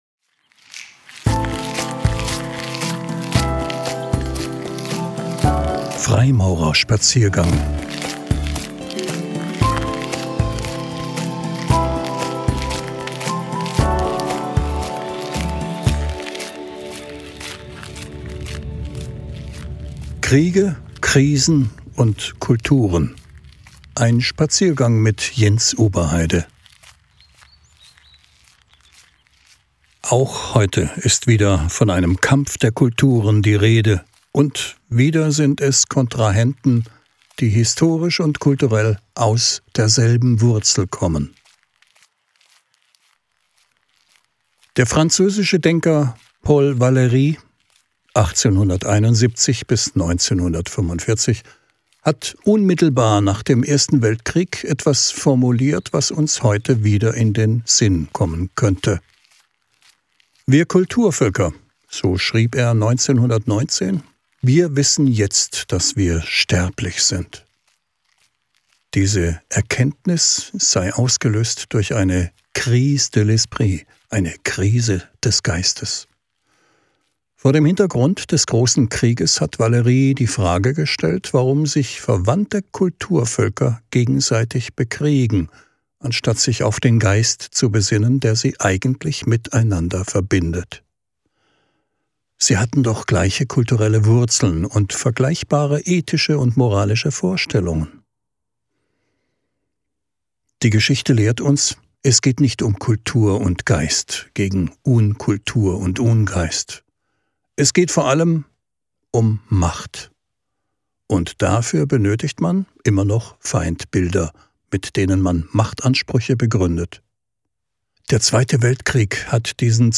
Ein Spaziergang